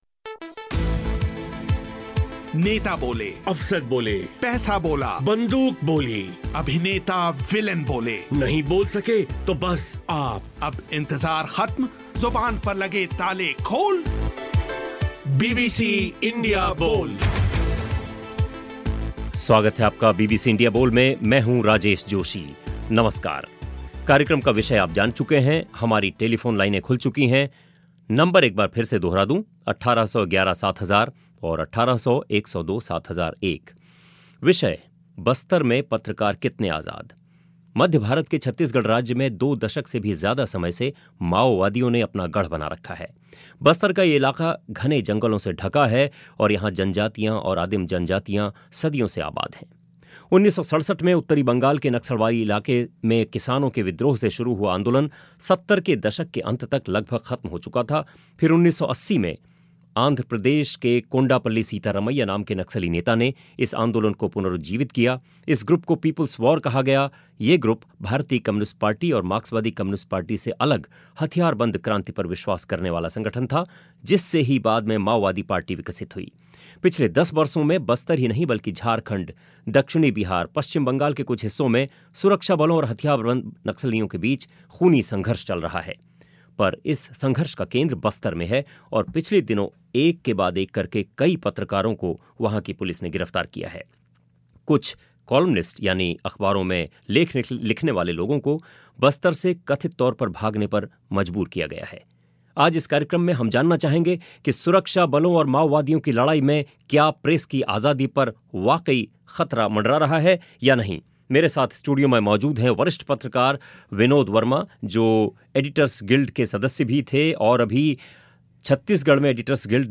सवाल ये है कि अगर पत्रकारों को काम करने की आज़ादी नहीं होगी तो माओवादियों और सरकार के बीच छिड़ी लड़ाई की सही तस्वीर कौन सामने लाएगा. बीबीसी इंडिया बोल इसी विषय पर हुई चर्चा.